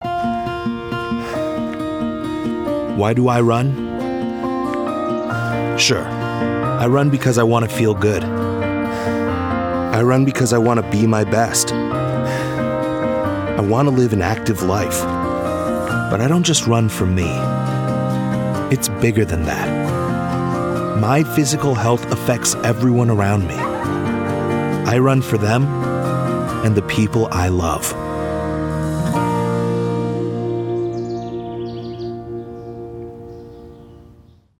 Teenager, Young Adult, Adult
Has Own Studio